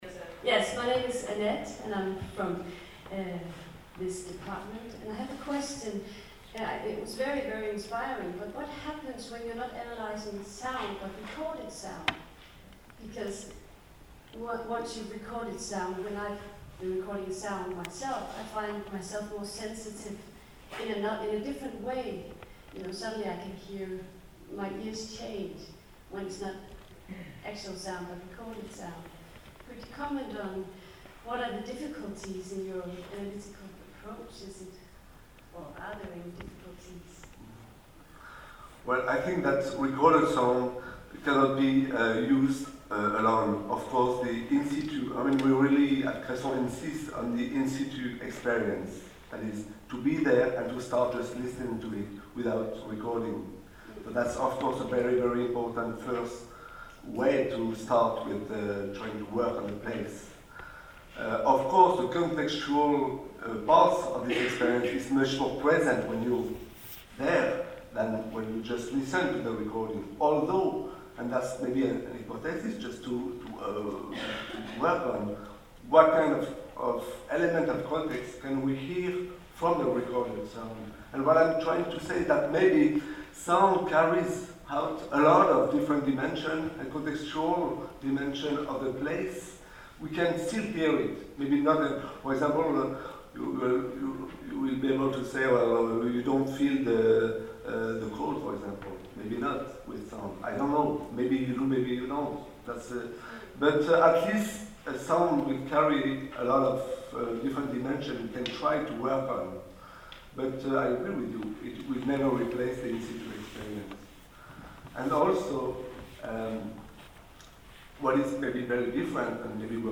Seminar and conference SoundActs, September 23 - 25, 2010, Aarhus University, Denmark, Sound as Art - Sound in Culture / Sound in Theory - Sound in History